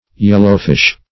Search Result for " yellowfish" : The Collaborative International Dictionary of English v.0.48: Yellowfish \Yel"low*fish`\, n. (Zool.)
yellowfish.mp3